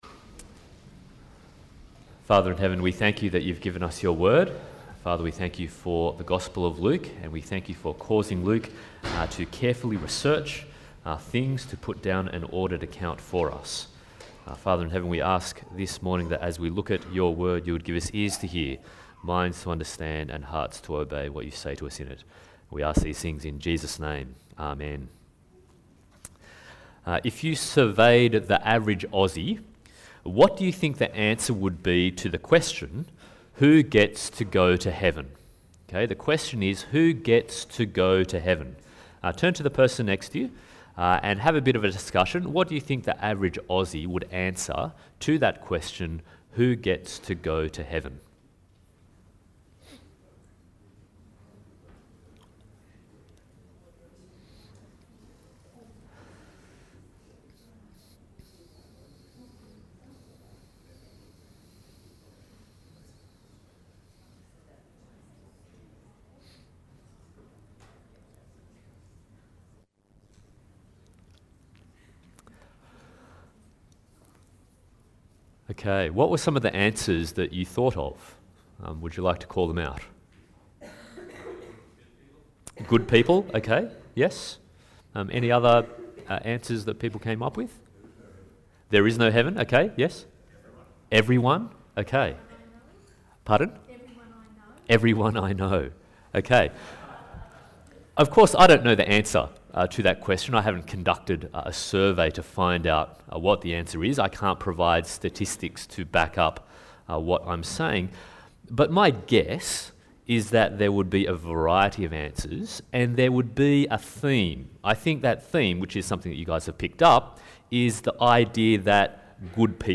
Luke 9-19 Passage: Luke 18:9-30, Psalm 149:1-9 Service Type: Sunday Morning « The Coming of the Son of Man Why Did The Son of Man Come?